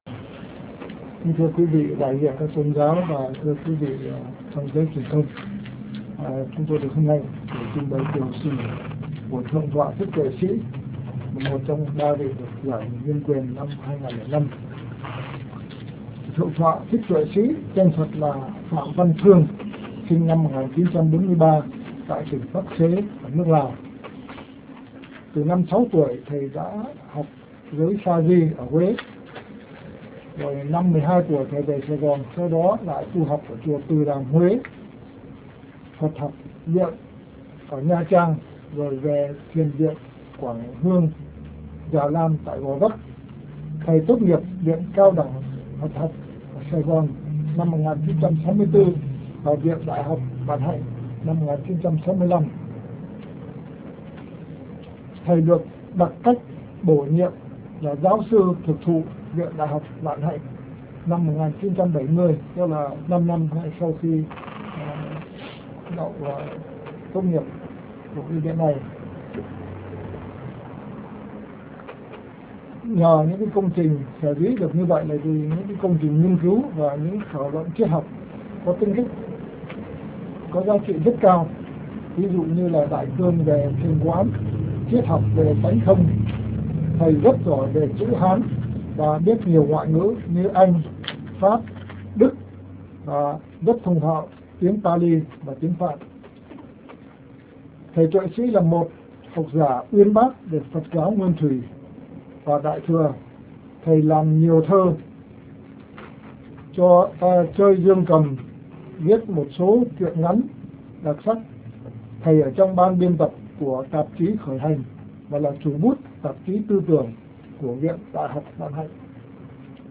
MẠNG LƯỚI NH�N QUYỀN HỌP B�O